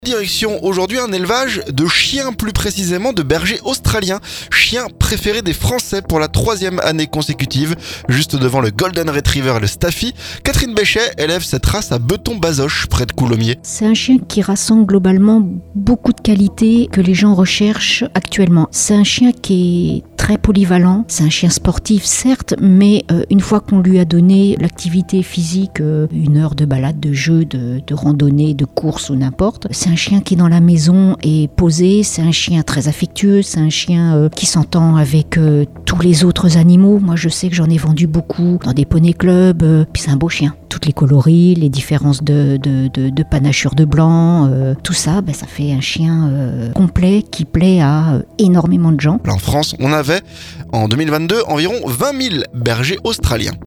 CHIEN PREFERE DES FRANCAIS - Reportage dans un élevage de bergers australiens, en Seine-et-Marne